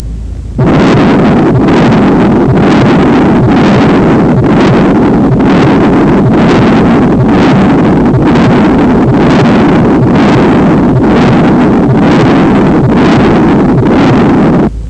Continuous murmur   เป็นเสียง murmur ที่ฟังได้ตลอดช่วงการบีบตัวและคลายตัวของหัวใจ